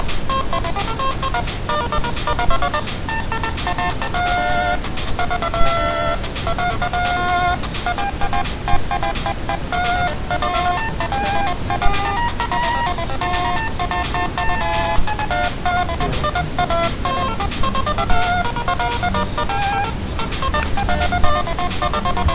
noice, very cool, but the down beat is off? XD